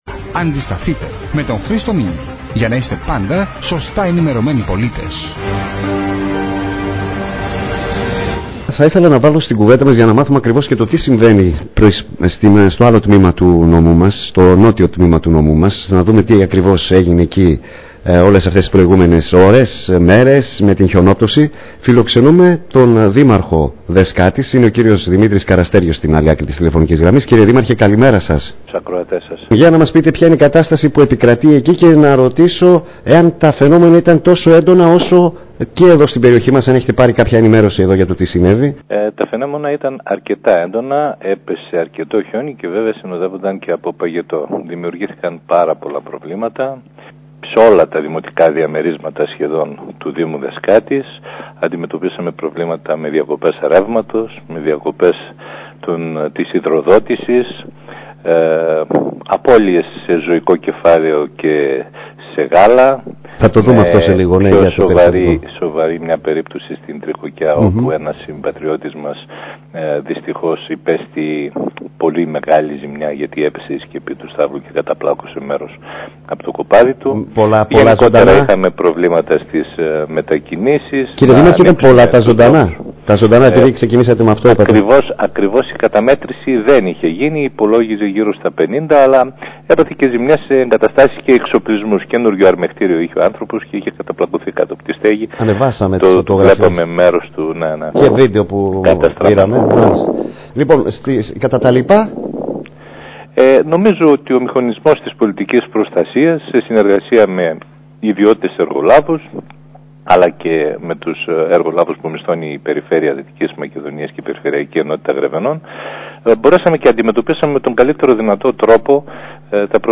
Για τα προβλήματα που δημιούργησαν τα έντονα καιρικά φαινόμενα που έπληξαν και τον Δήμο Δεσκάτης, μίλησε την Παρασκευή (20-1-17), στον Star-fm 9 33, ο Δήμαρχος Δεσκάτης Δημήτρης Καραστέργιος.